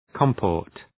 Προφορά
{‘kɒmpɔ:rt}